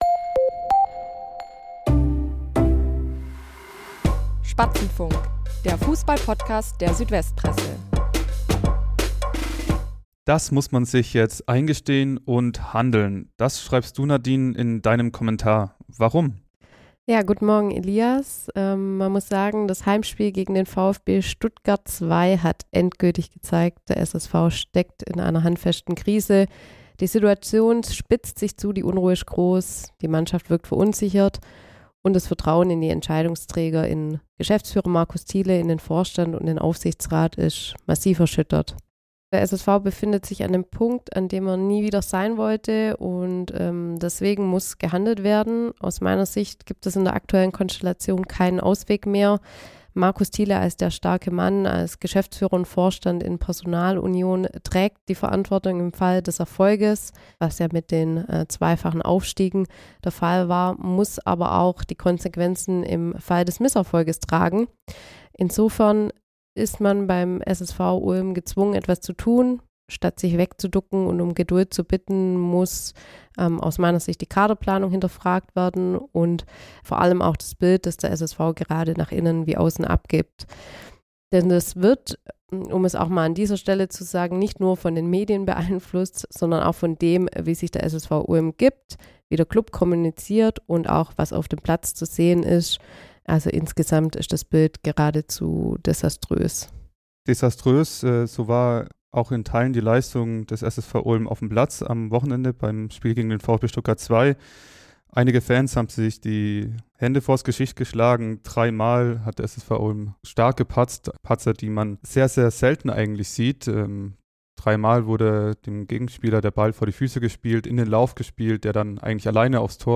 Die Sportreporter